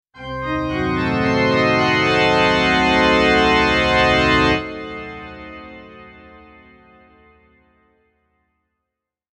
Spooky Church Organ Transition Sound Effect
Description: Spooky church organ transition sound effect. An eerie and haunting famous organ musical phrase that instantly creates a sense of tension and mystery.
Genres: Sound Effects
Spooky-church-organ-transition-sound-effect.mp3